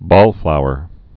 (bôlflouər)